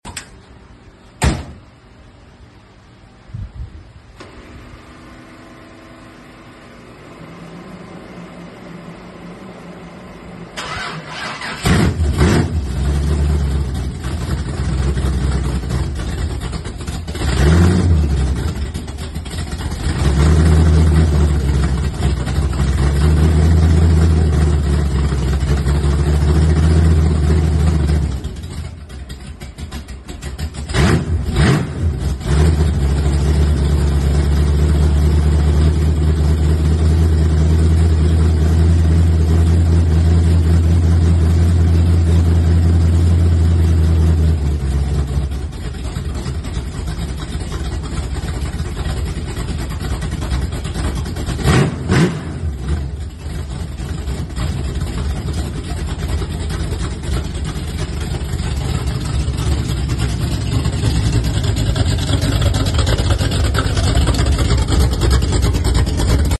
First time cold starting my new toy 1986 Foxbody SBF 393stroker SVO Block SVO heads.